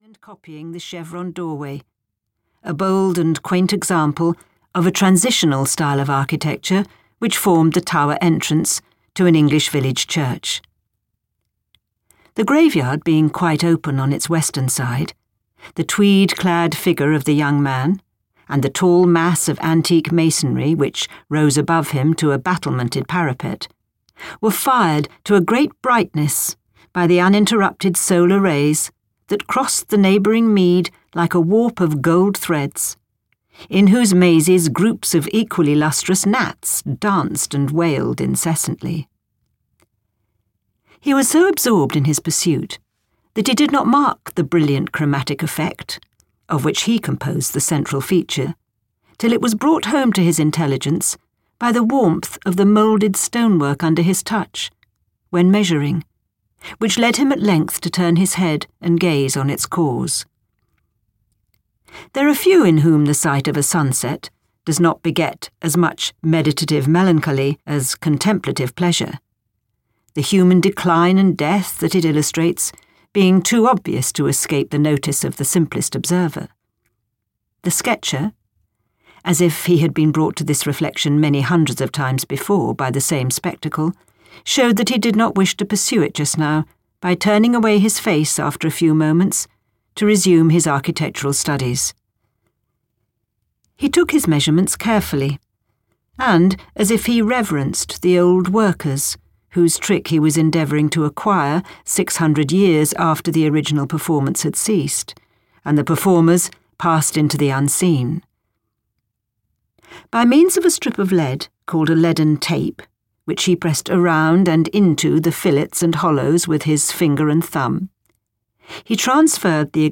A Laodicean (EN) audiokniha
Ukázka z knihy